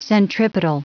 Prononciation du mot centripetal en anglais (fichier audio)
Prononciation du mot : centripetal
centripetal.wav